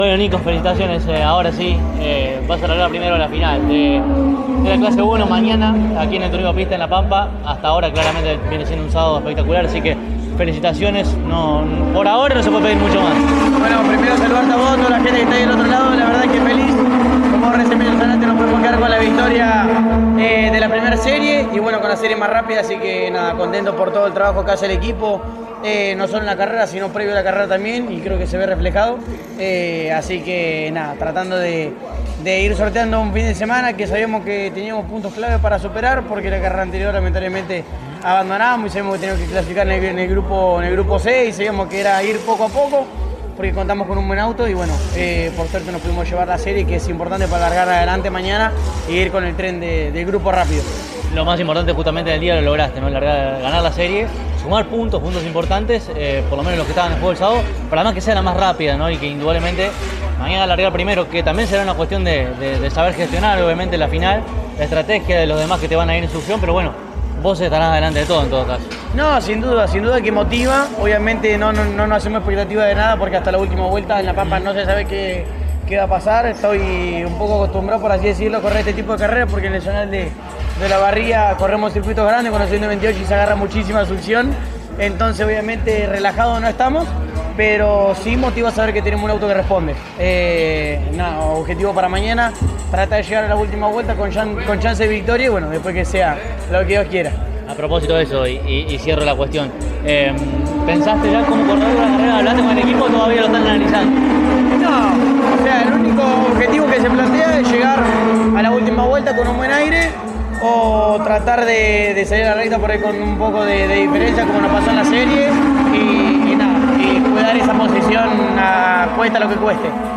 en diálogo con CÓRDOBA COMPETICIÓN: